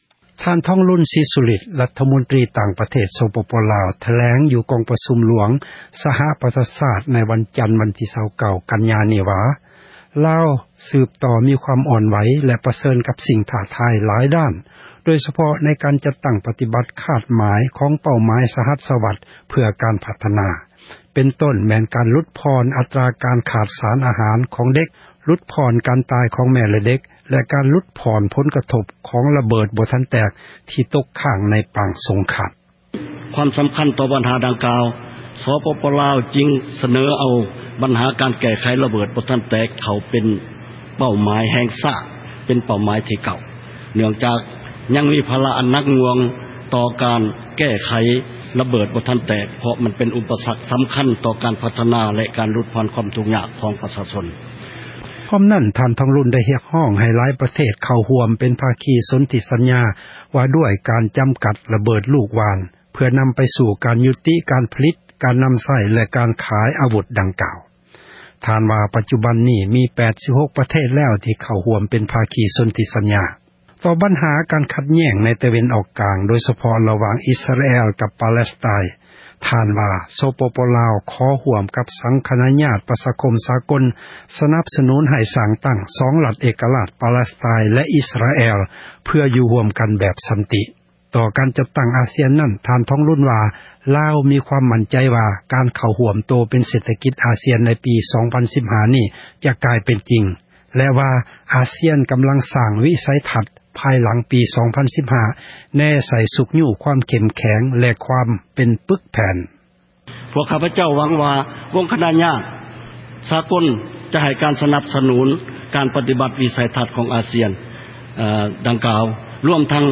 f-default ທ.ທອງລຸນ ສີສຸລິດ ຣັຖມົນຕຣີ ຕ່າງປະເທດລາວ ຖແລງຢູ່ກອງປະຊຸມຫລວງ ສະຫະປະຊາຊາຕ ໃນວັນຈັນ ວັນທີ່ 29 ກັນຍາ